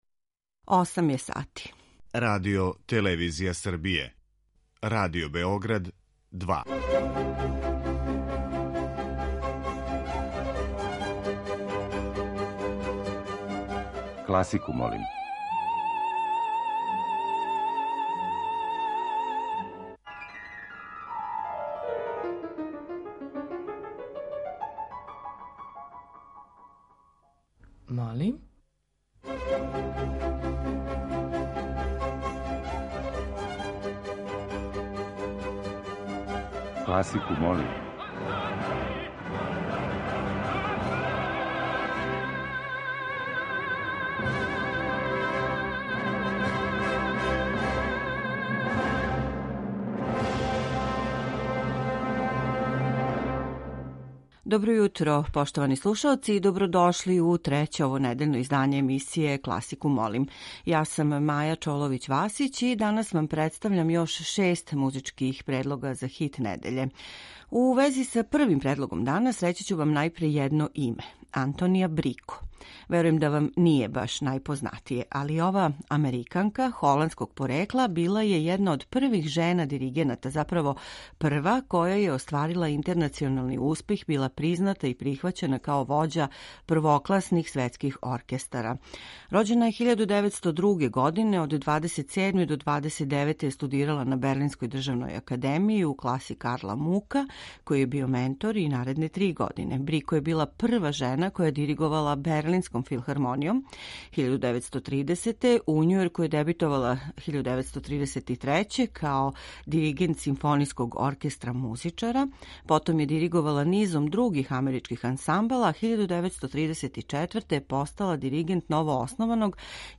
Тема циклуса - снимци са овогодишњих Мокрањчевих дана.